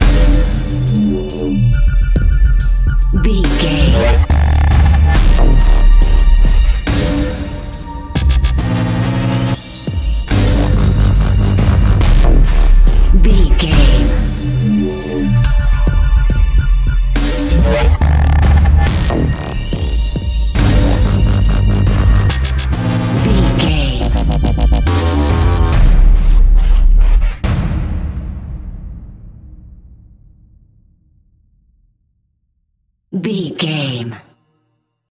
Aeolian/Minor
D
orchestral hybrid
dubstep
aggressive
energetic
intense
strings
drums
bass
synth effects
wobbles
driving drum beat
epic